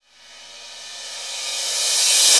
Crashes & Cymbals
Maestro Reverse Cymbal OS.wav